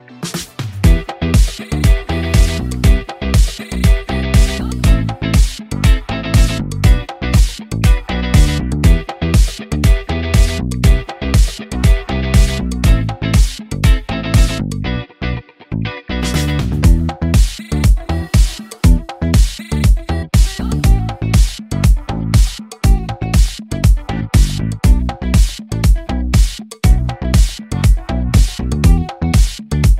из Танцевальные